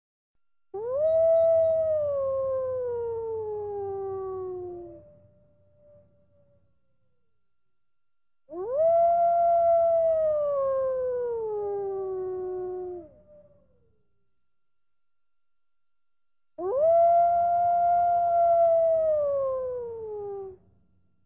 Hurlement de loup
loup.mp3